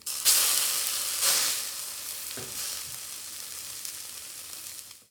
action_cook_1.ogg